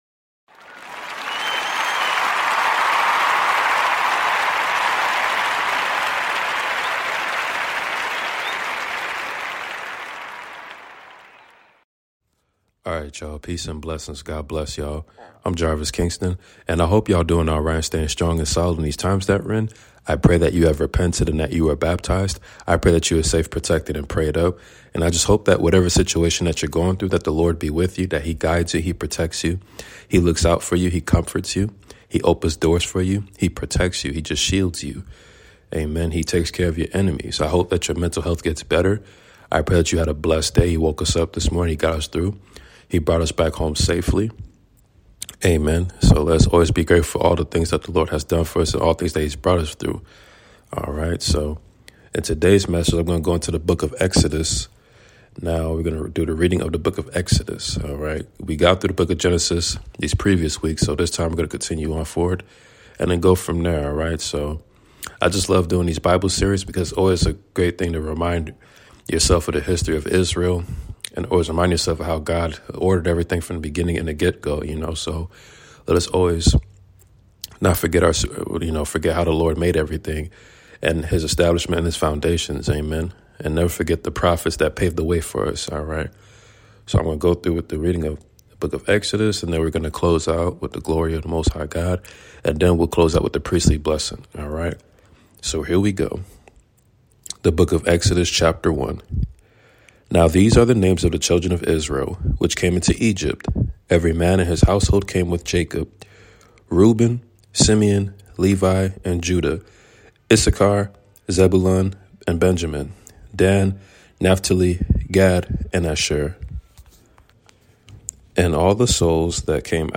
Book of Exodus reading ! The Lord is the true deliver !!!!!!!